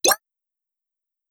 jump.wav